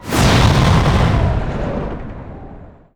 hellfire_blast.wav